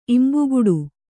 ♪ imbuguḍu